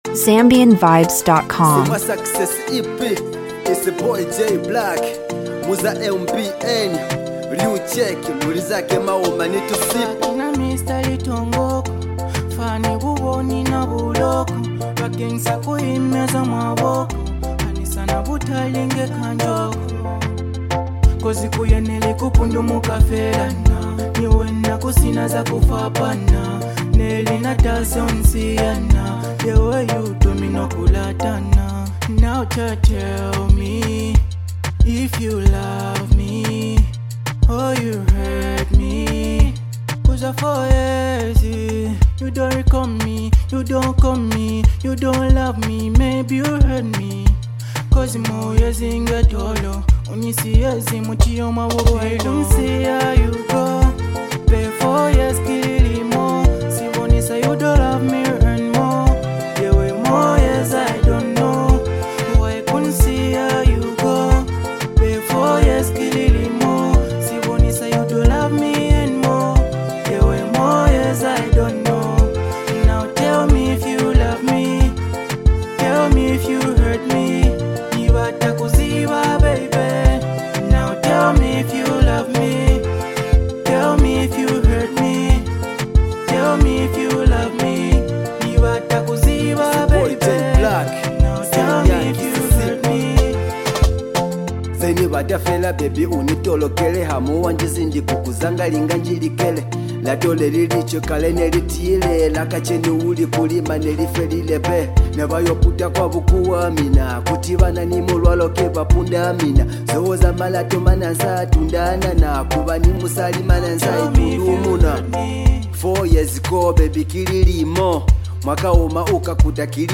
vibrant sound